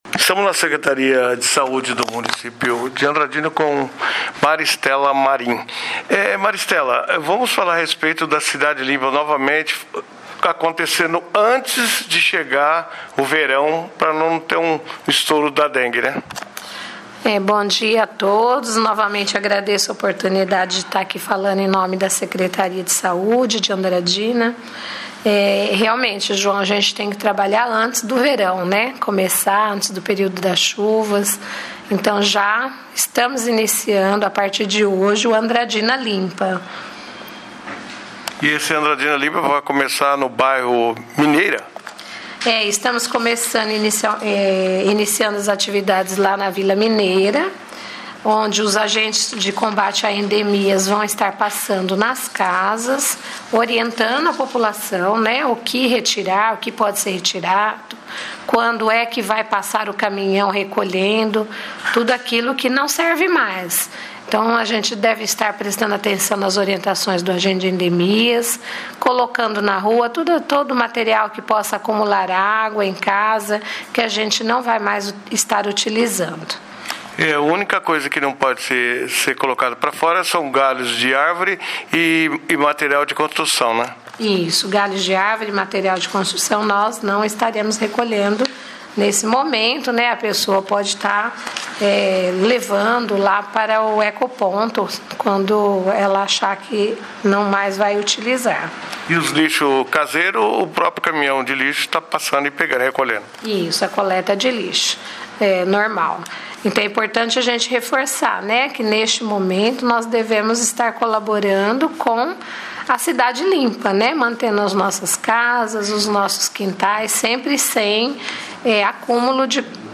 A secretária de Saúde, Maristela Marinho, explicou em entrevista exclusiva à Rádio Metrópole